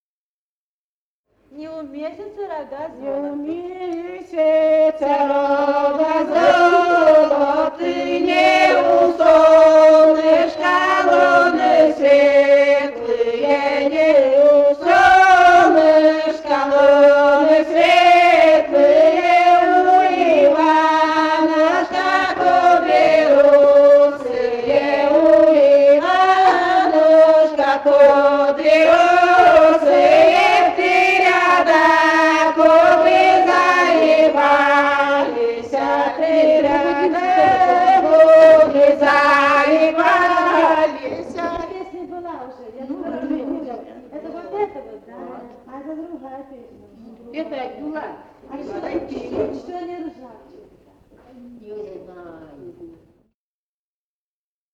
«Не у месяца рога золоты» (свадебная).